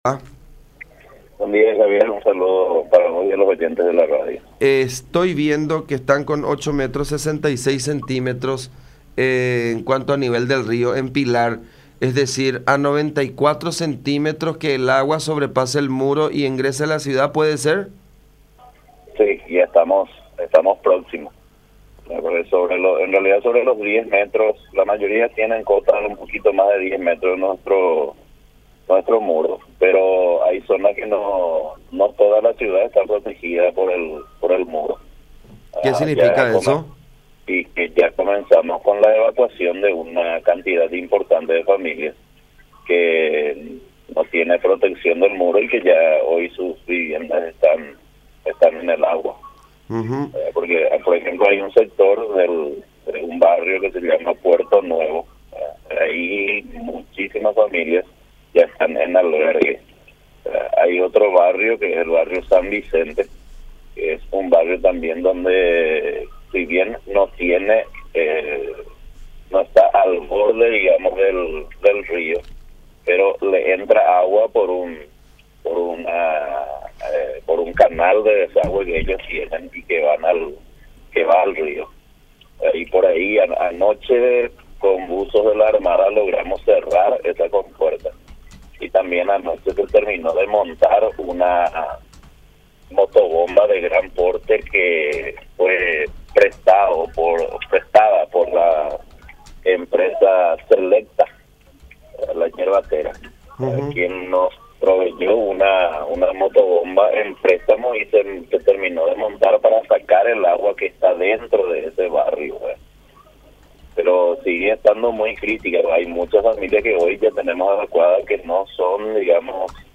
Cerca 100 familias de los barrios Puerto Nuevo y San Vicente de Pilar fueron evacuadas debido al desborde el Río Paraguay. Fueron llevadas a albergues cercanos, según informó en diálogo con La Unión Diosnel Aguilera, concejal de Pilar.